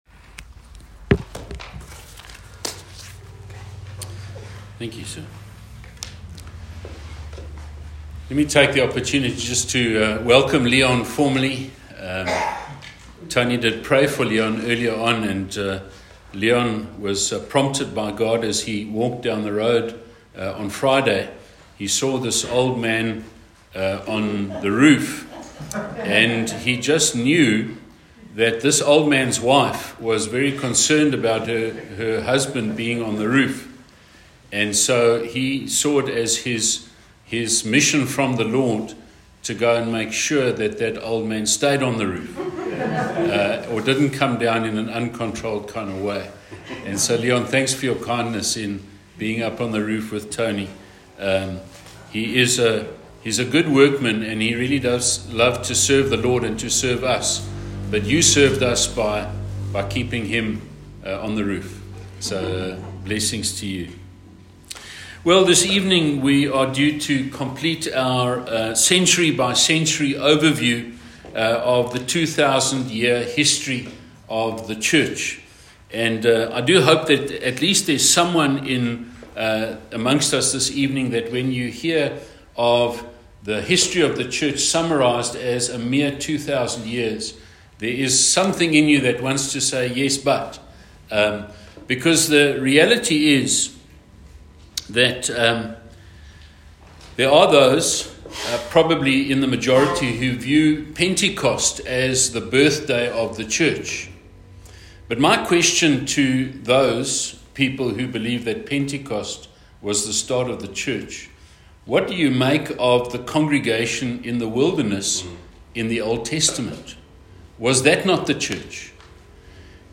A message from the series "Church History."